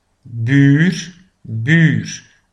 de buur PRONONCIATION